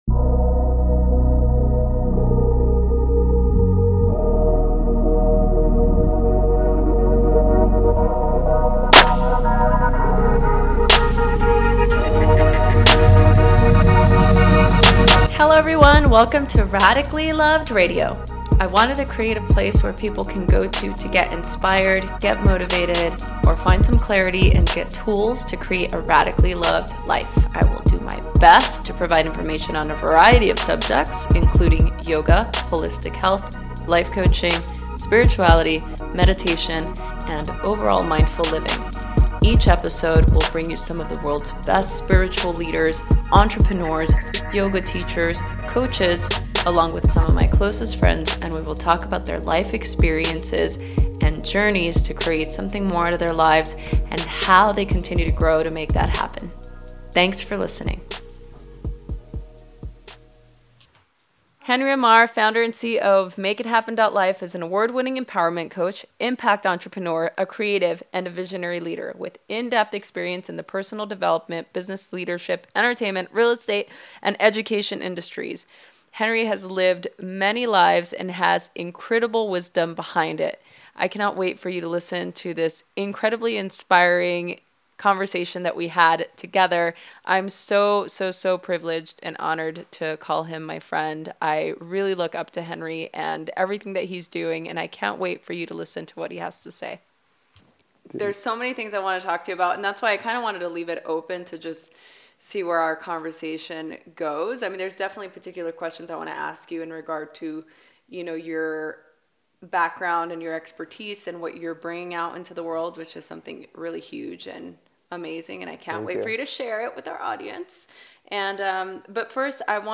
It was a privilege to have this conversation with him.